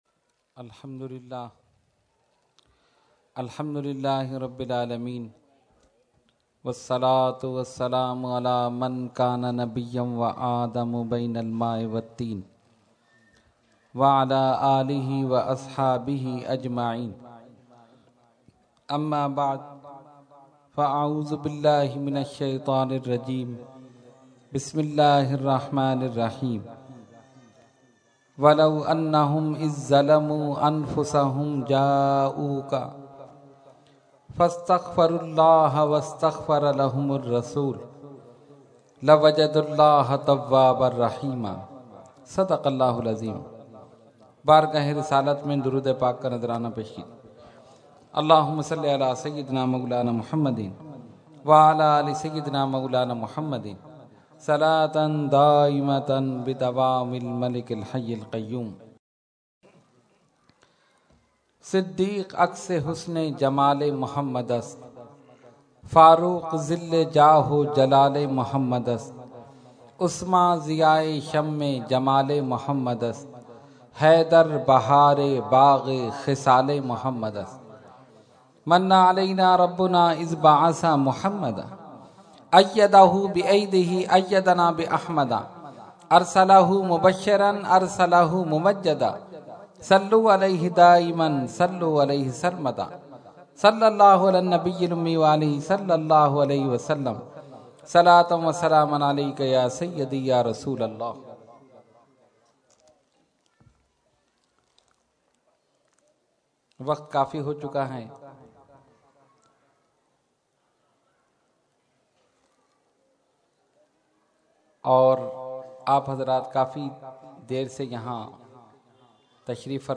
Category : Speech | Language : UrduEvent : Mehfil 11veen North Nazimabad 31 March 2014